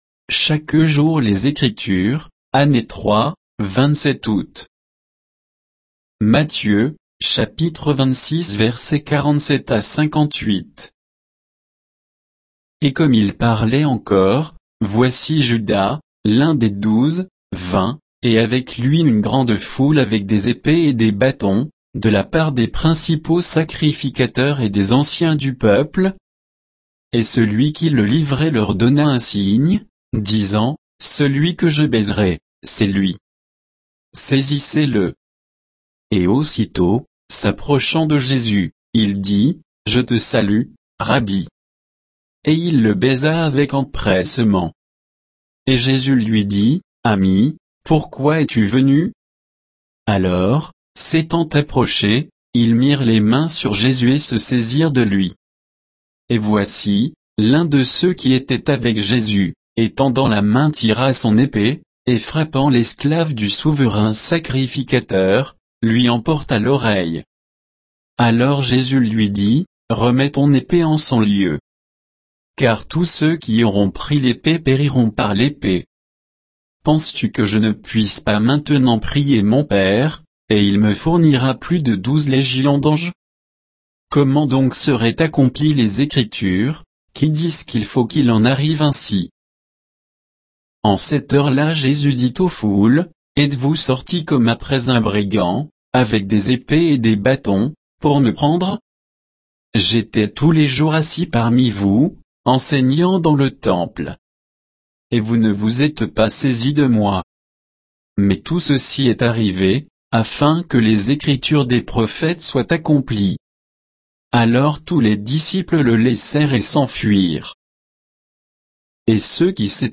Méditation quoditienne de Chaque jour les Écritures sur Matthieu 26